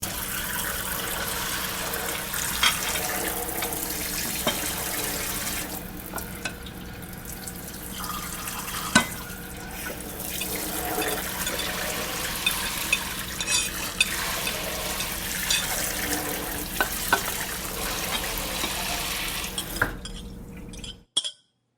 Hand Washing Dished in the Kithcen
SFX
yt_vsNv7wigLd8_hand_washing_dished_in_the_kithcen.mp3